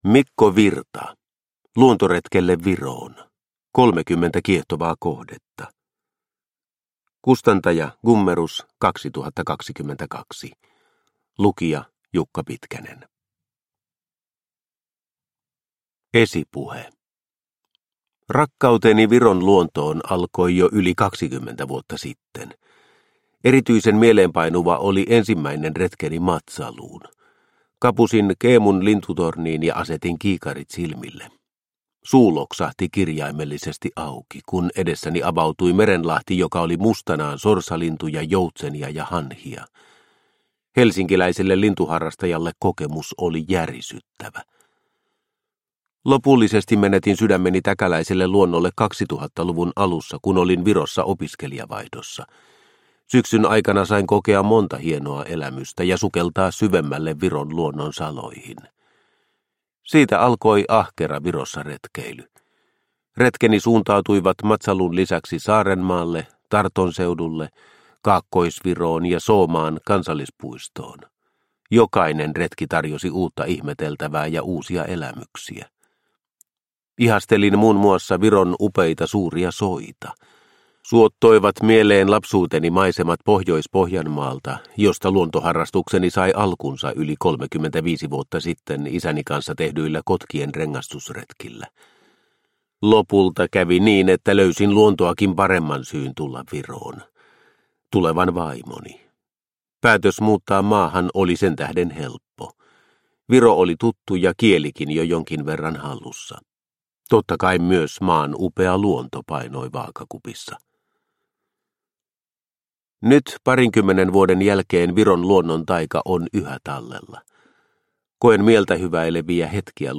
Luontoretkelle Viroon – Ljudbok – Laddas ner